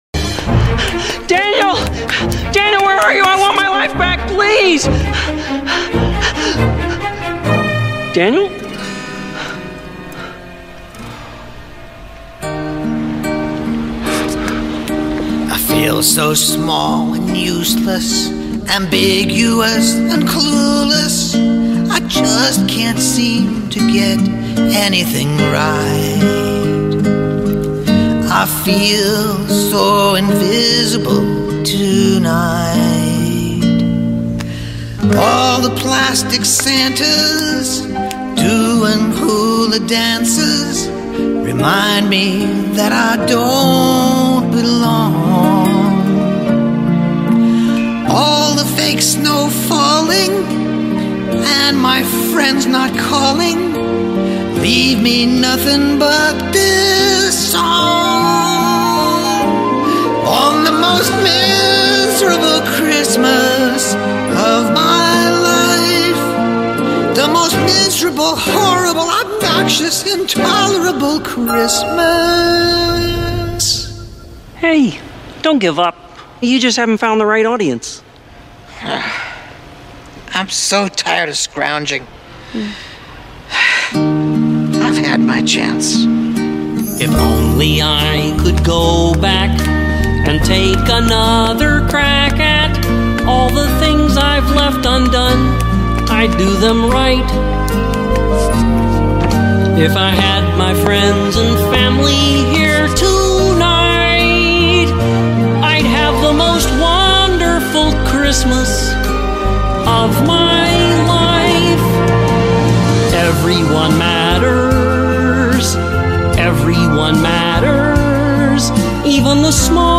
very special and bittersweet Christmas song